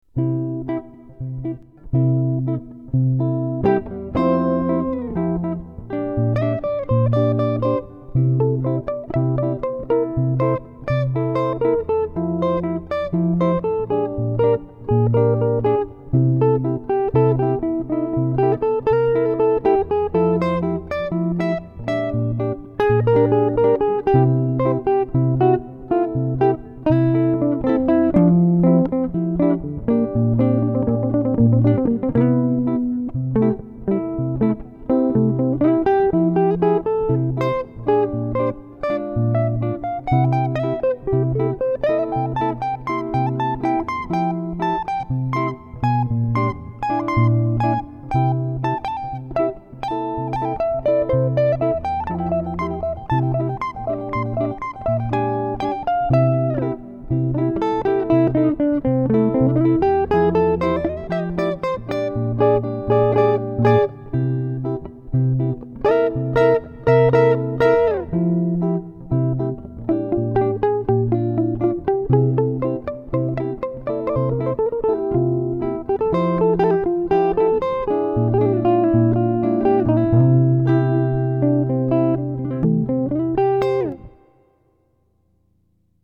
J'ai entendu 4 accords de bossa nova...et une impro qui ne suit pas du tout le voicing des accords...restant tranquillement pentatonique....avec donc des notes hum hum...C'est pourtant quelqu'un que j'ai découvert sur ce forum et dont chaque affirmation semble être pour les users plus débutants parole d'évangile.